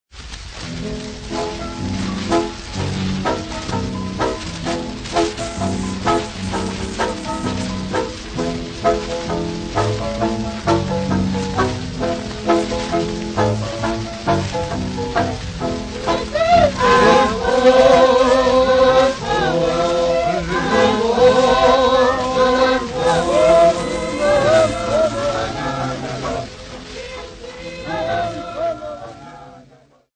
American Pride Singers
Folk Music
Field recordings
Africa South Africa Kwa-Zulu Natal f-sa
sound recording-musical
Indigenous music